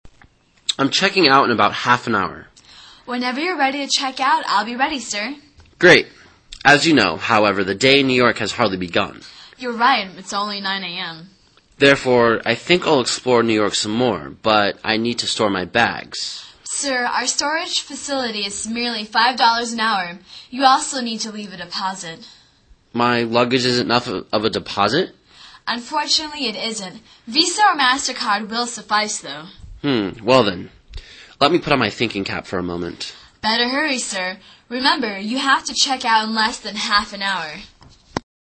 旅馆英语对话-Storing Luggage(1) 听力文件下载—在线英语听力室